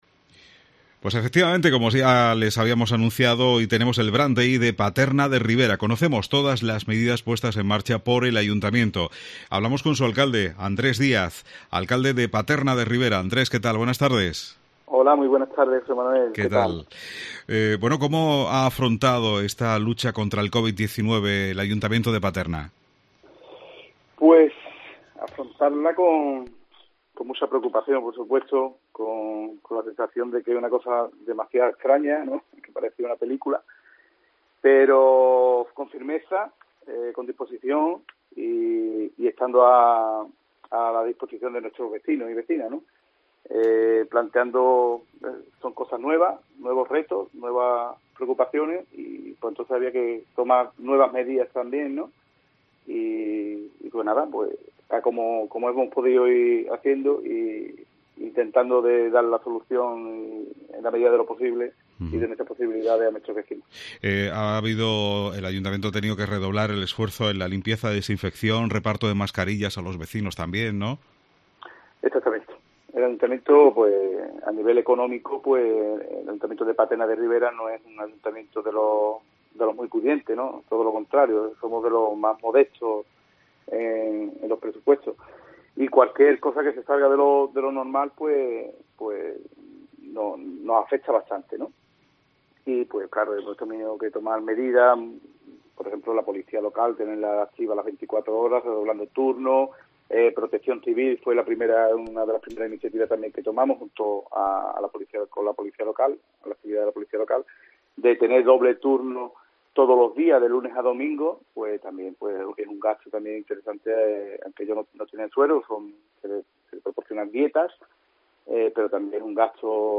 Andrés Díaz, alcalde de Paterna de Rivera, en el Brand Day de COPE